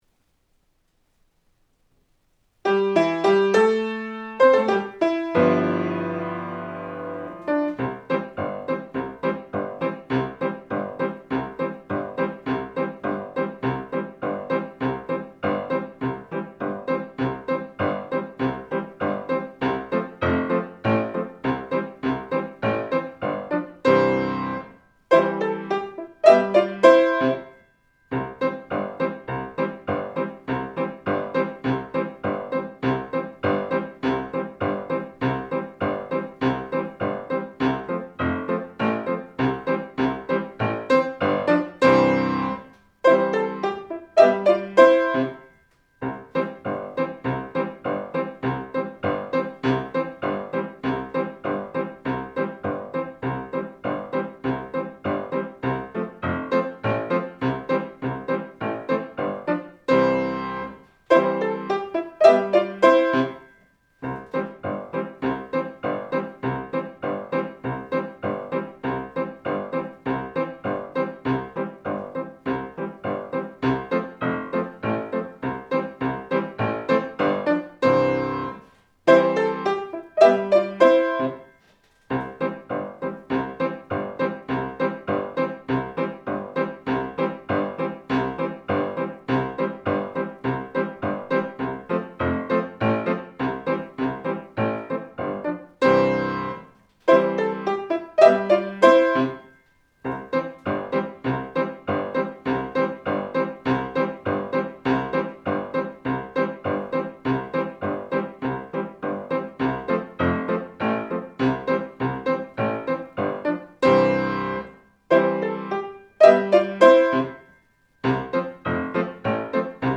⭐予選審査では、下記の課題曲の伴奏をご利用いただくことも可能です。
あわて床屋前奏３小節